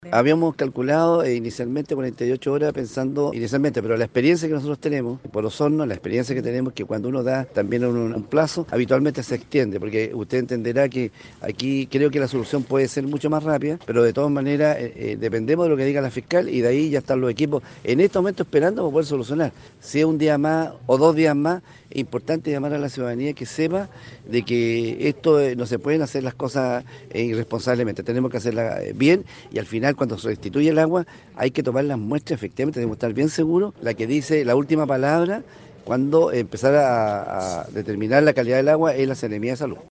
La permanencia en la interrupción del servicio de agua potable en la villa de Puerto Octay también generó que las actividades de fiestas patrias sean en parte suspendidas, tal como el desfile cívico militar que se realizaría este viernes, dijo la alcaldesa.